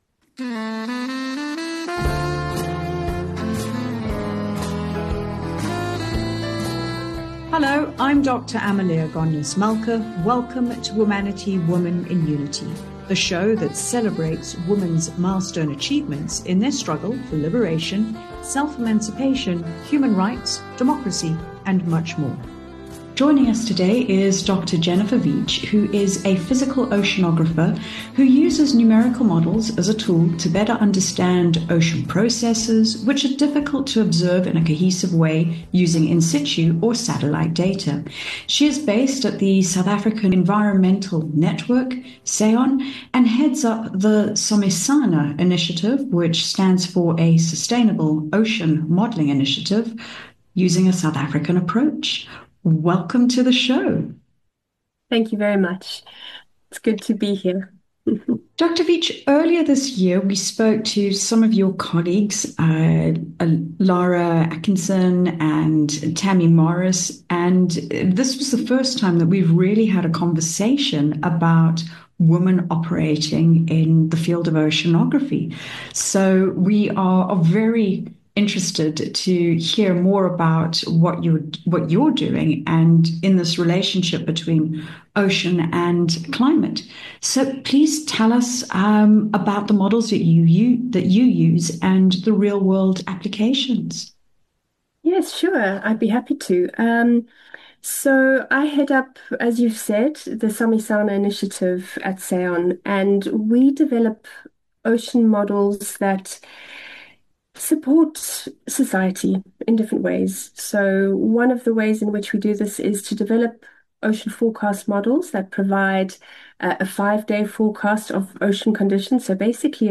Bridging Science and Society A major theme in the interview is the essential interplay between science and society.